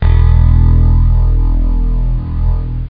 ominous.mp3